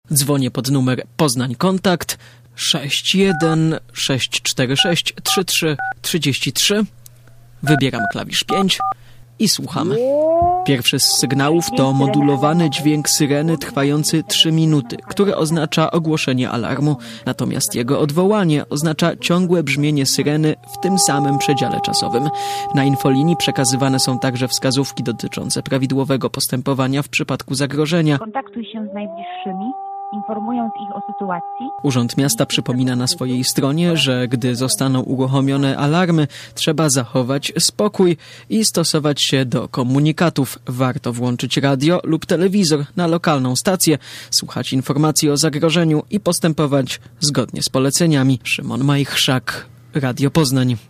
Poznaniacy mogą przez telefon posłuchać charakterystycznych dźwięków syreny i dowiedzieć się, co zrobić, gdy zostaną uruchomione.
syreny alarmowe - UMP
Pierwszy z sygnałów to modulowany dźwięk syreny trwający trzy minuty, który oznacza ogłoszenie alarmu. Natomiast ciągłe brzmienie syreny w tym samym przedziale czasowym oznacza jego odwołanie.